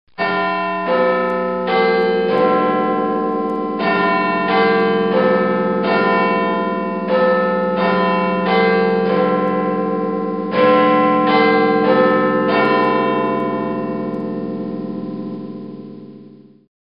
Big_Ben_tornimuusika.mp3